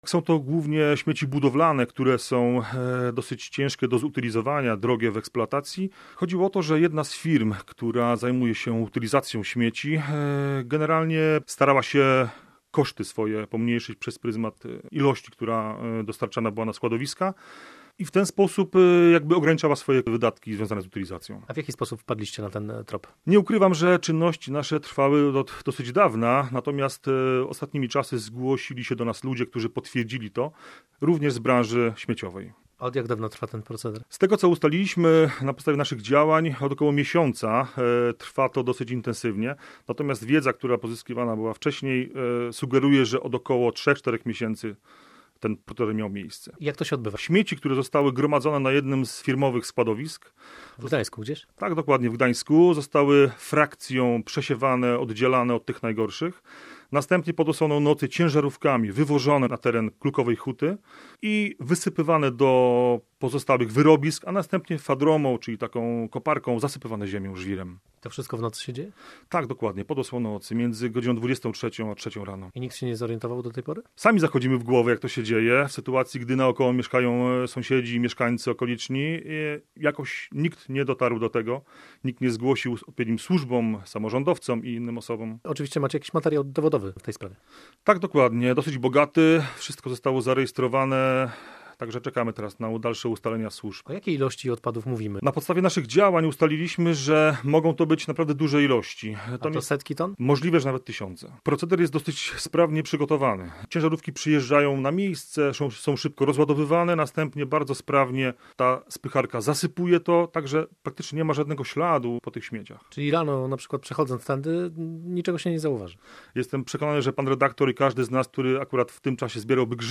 Skandaliczny proceder firmy z Gdańska i tony nielegalnych odpadów pod Stężycą [NASZ REPORTER NA MIEJSCU]
Na miejsce pojechał nasz wóz satelitarny.